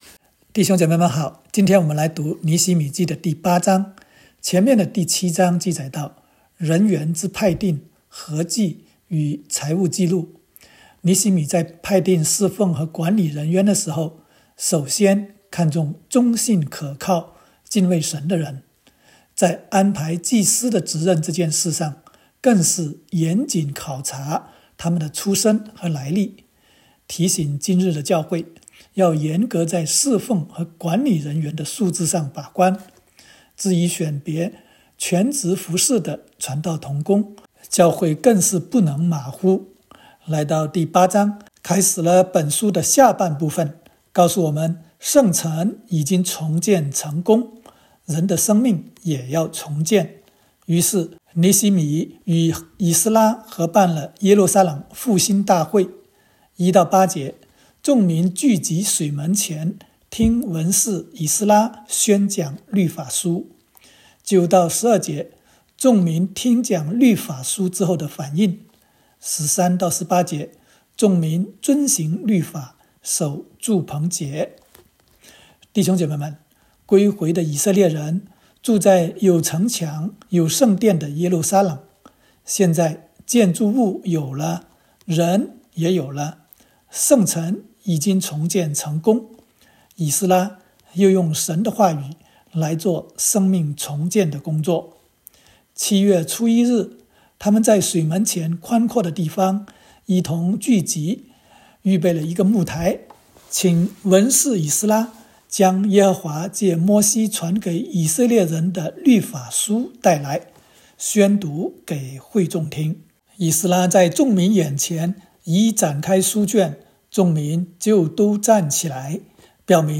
尼08（讲解-国）.m4a